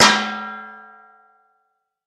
Звуки сковородки
Звук удара металлического ковша о сковородку